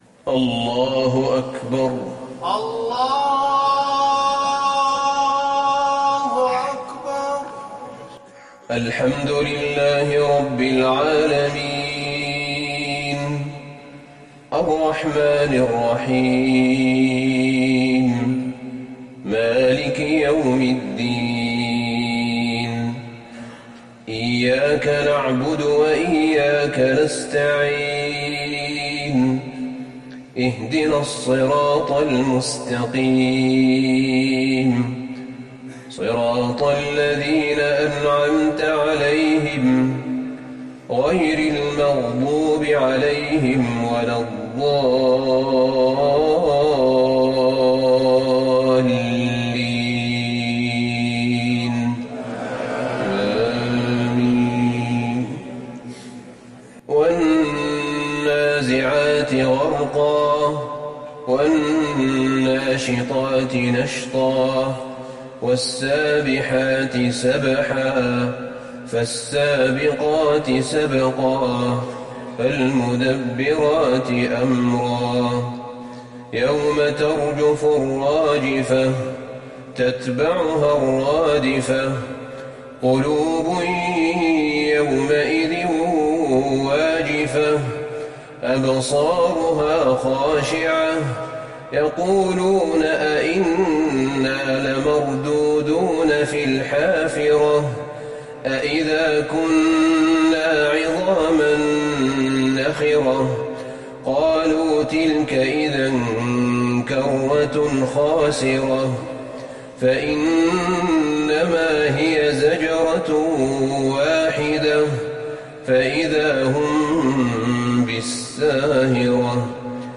صلاة العشاء للشيخ أحمد بن طالب حميد 21 جمادي الأول 1441 هـ
تِلَاوَات الْحَرَمَيْن .